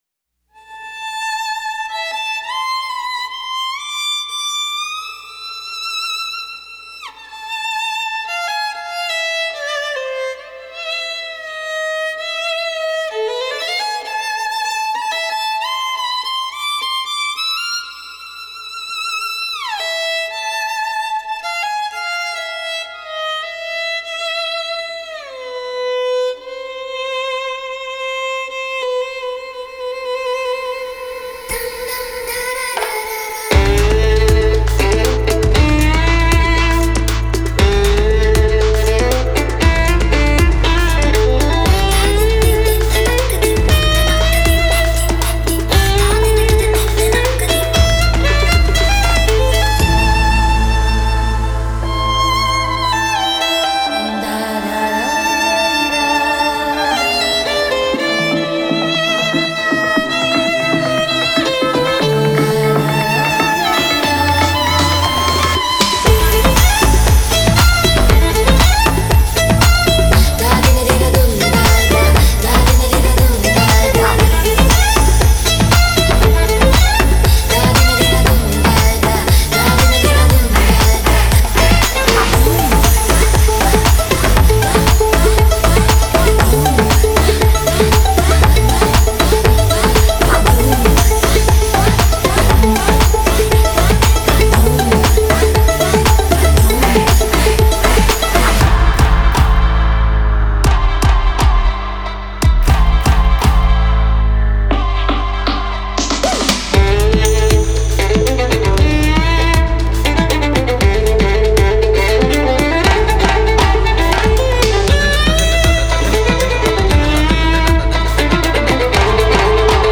Genre : Classical, Electronic